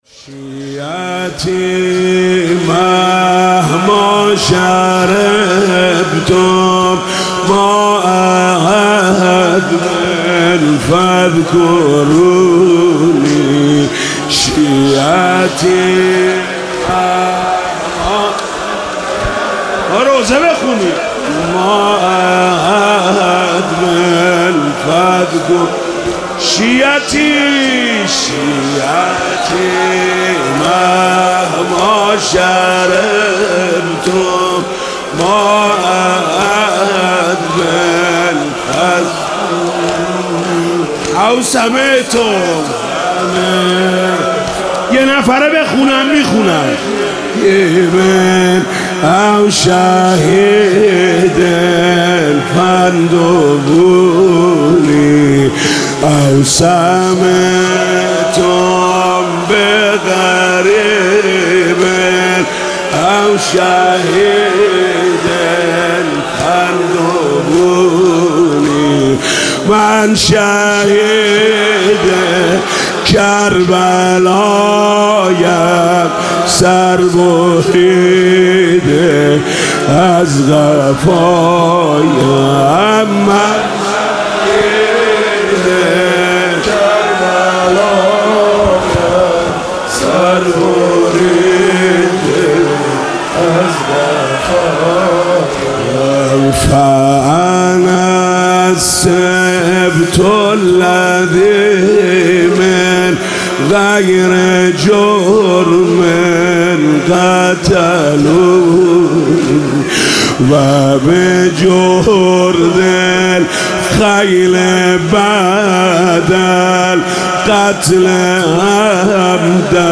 شام غریبان
روضه